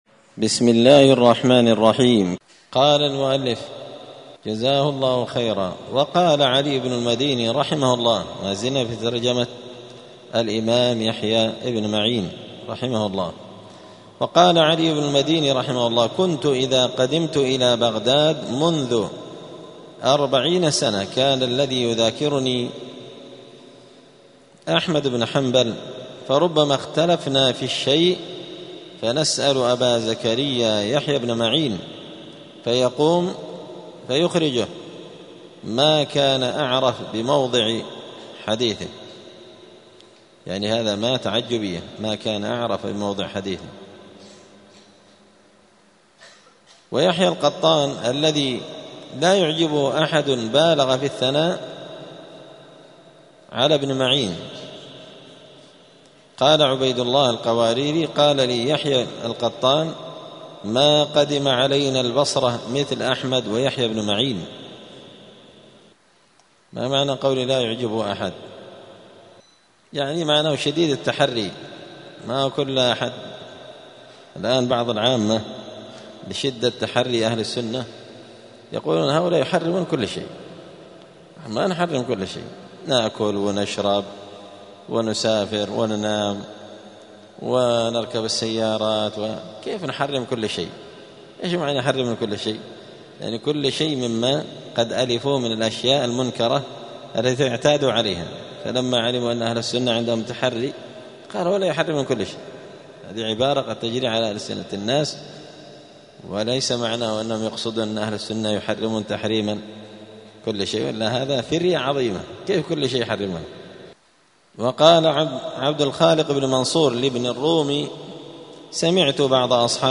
*الدرس الخامس والثمانون (85) باب التعريف با لنقاد يحيى بن معين*